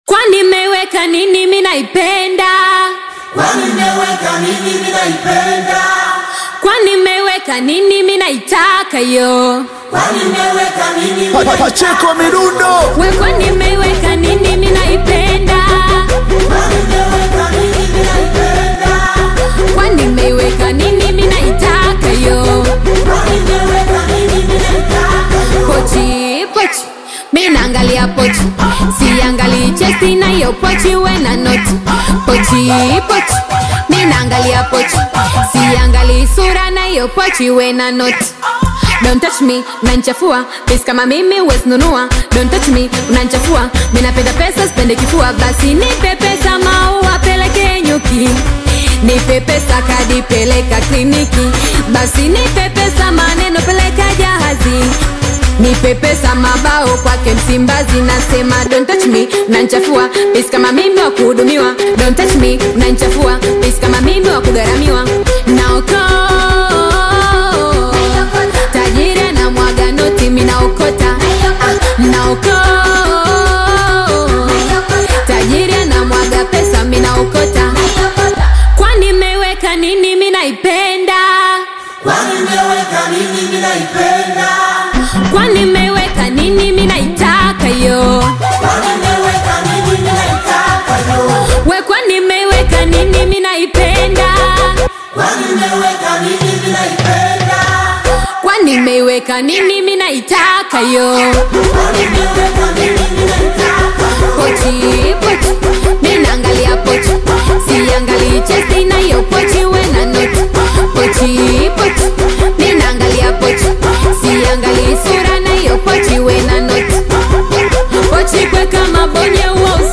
Singeli music track
Bongo Flava